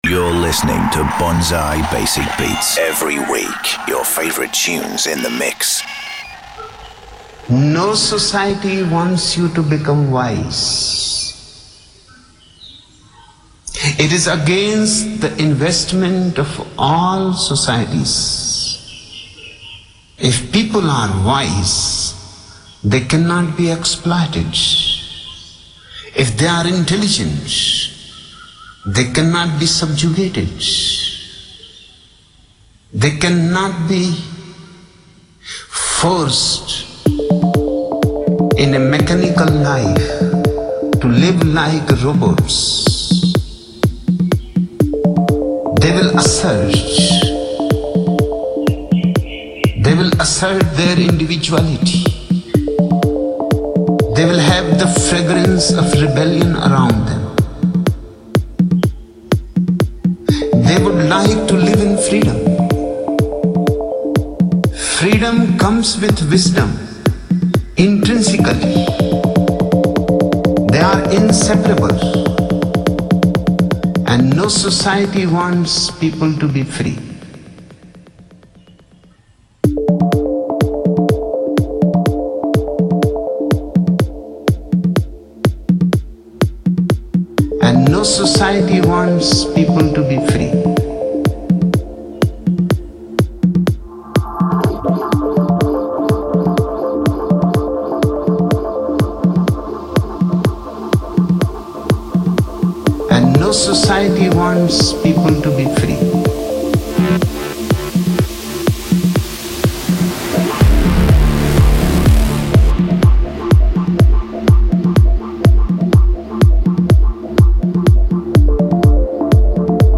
Also find other EDM Livesets, DJ Mixes and Radio Show
your weekly fix of underground grooves.
who delivers a mix full of energy and flow.